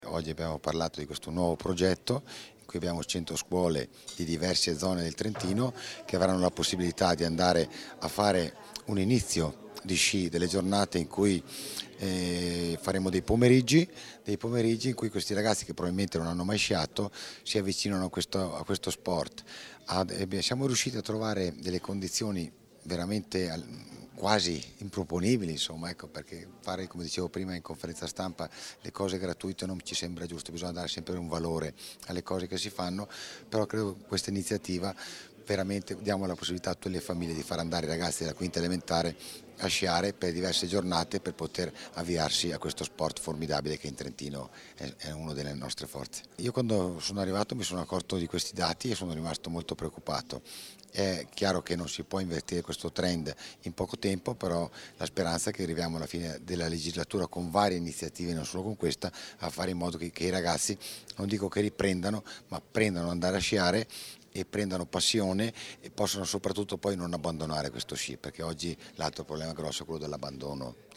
Interv_Failoni_Prog_100Classi_sulla_neve.mp3